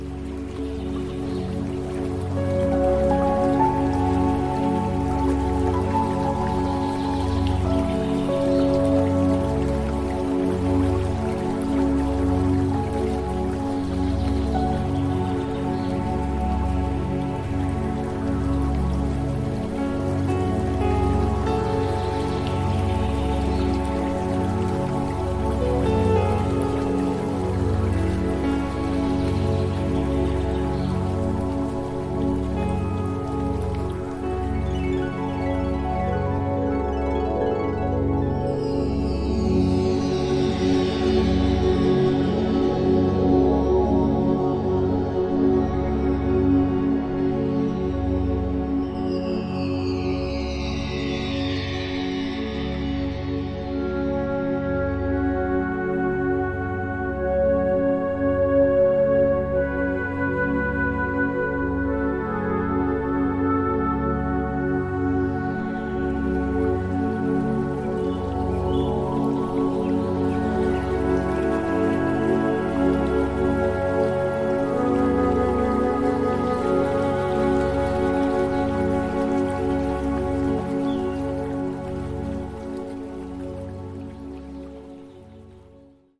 UPLIFTING AND REBALANCING
Recorded in 432 Hz…the natural healing frequency of Earth.